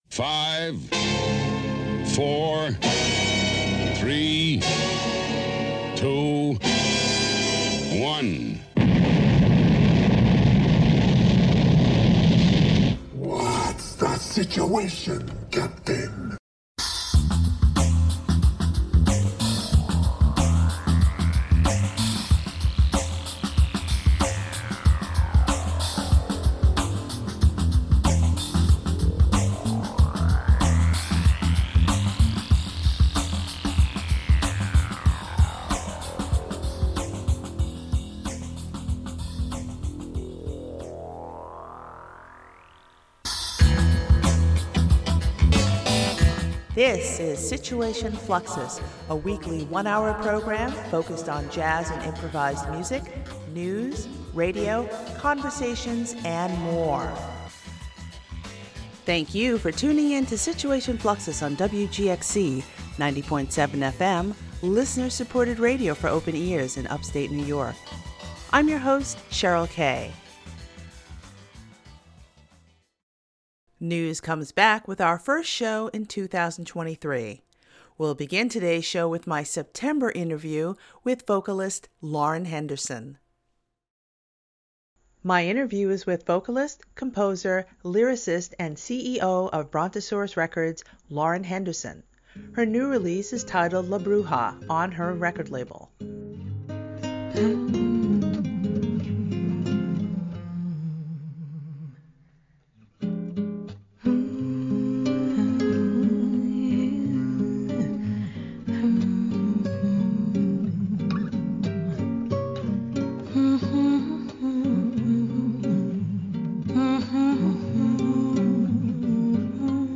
Situation Fluxus is a weekly one-hour program focused on jazz and improvised music, news, radio, conversations, and more.